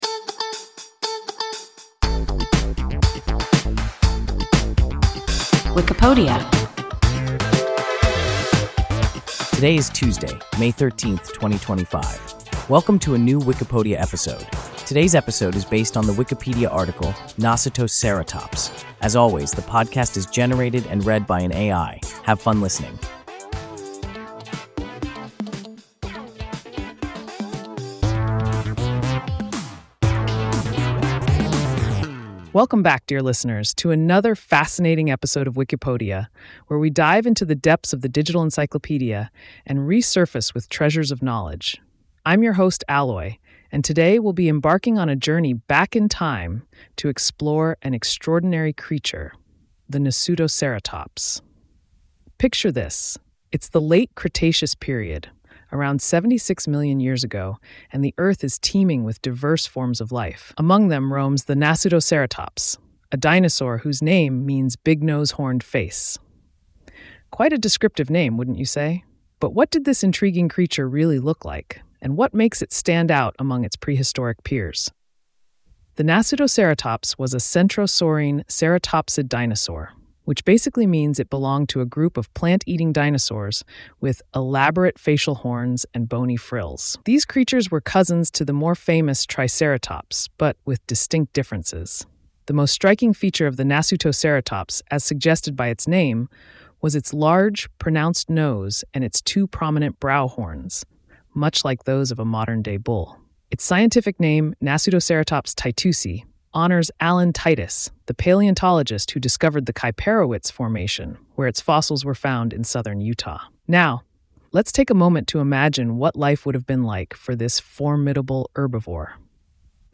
Nasutoceratops – WIKIPODIA – ein KI Podcast